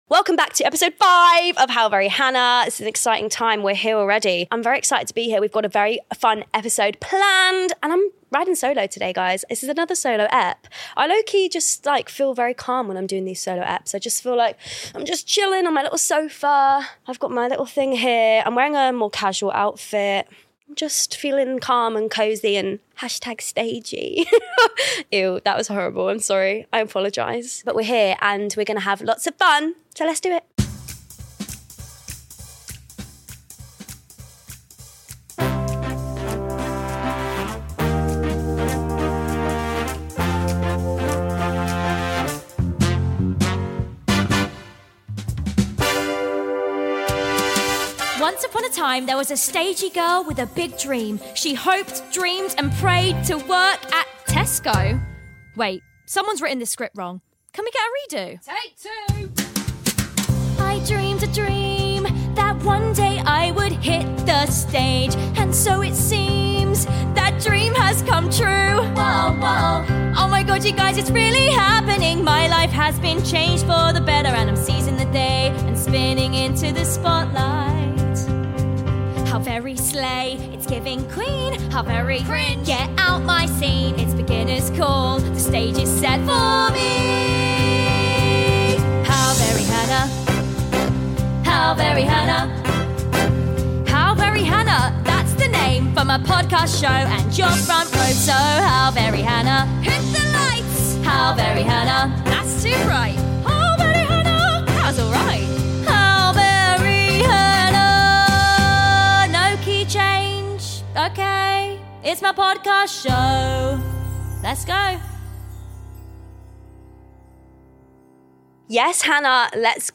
It’s a solo(ish) episode... but wait, who’s that at the curtain?! 👀🐶🎭